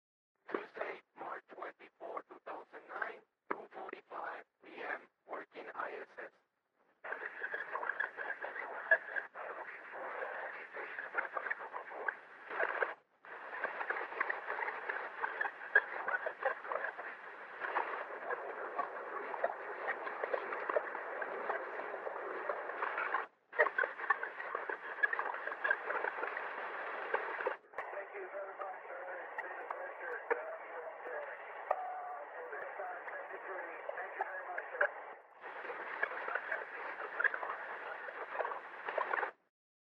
ISS Pass Over Puerto Rico & Joseph Acaba Was Calling Puertorican Stations on March 24, 2009 At 2:45pm Local Time.